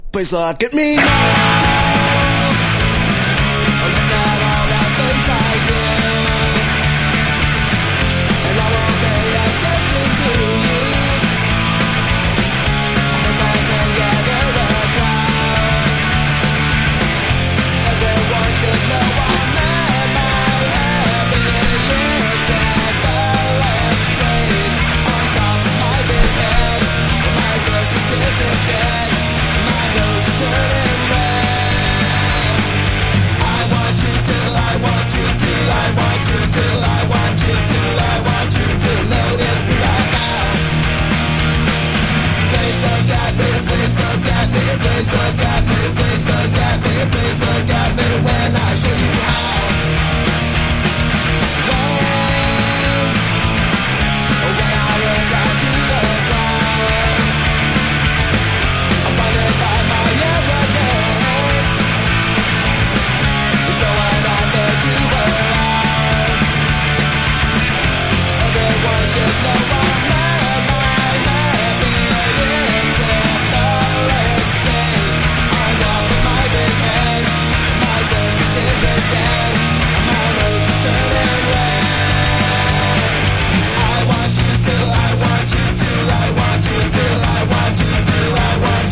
カリフォルニアのメロディック･パンク･バンド
甘酸っぱいボーカルにどこまでもキャッチーなメロディー！！